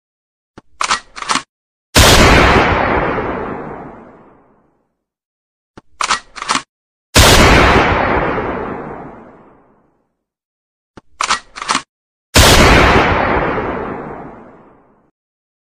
Kategori: Nada dering
Keterangan: Nada dering WA suara tembakan PUBG Keren, ringtone tembakan Pubg...